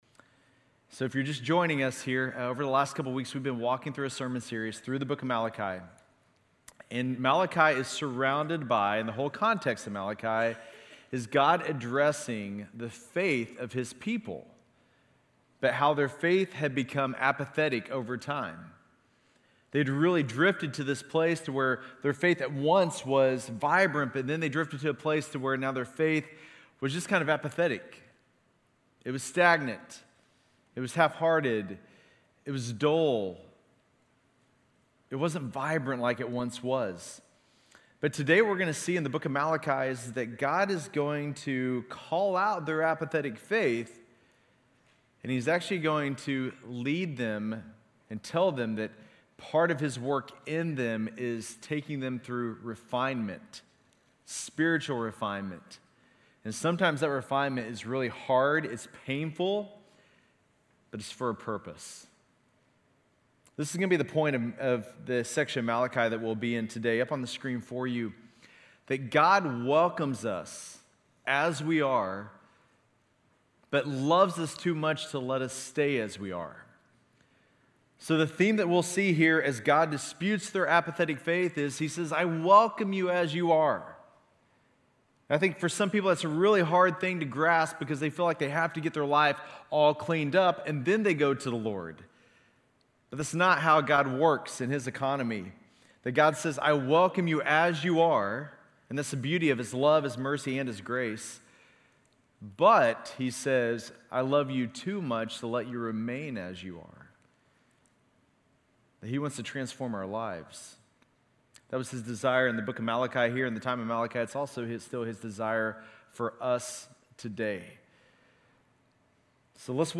GCC-UB-October-30-Sermon.mp3